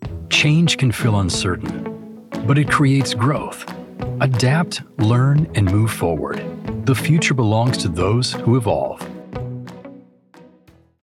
Conversational Male Voice-Commercial, Corporate Narration & E-Learning
Conversational, Warm and Friendly Narrator for Corporate Training
Animation Explainer Trustworthy Tone Demo.mp3